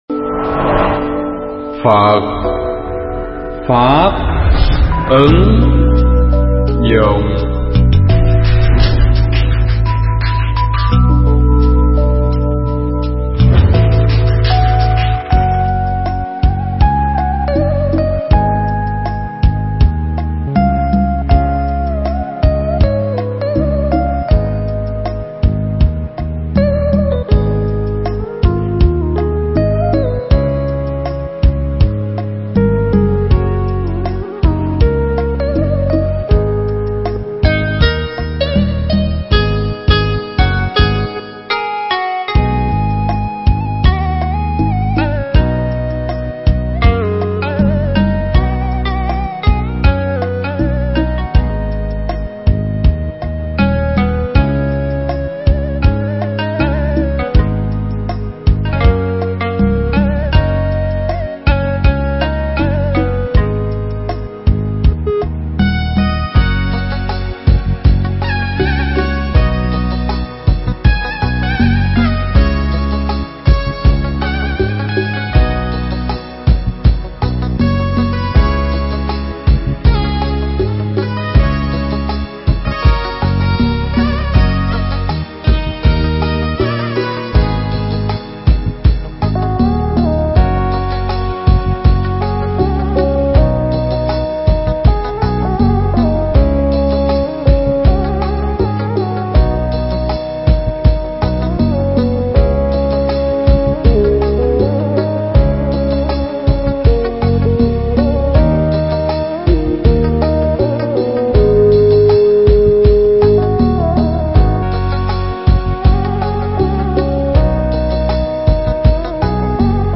Thuyết pháp Nhìn Bằng Chánh Kiến
tại chùa Phổ Đà (Đức Quốc) trong chuyến hoằng pháp tại Châu Âu năm 2015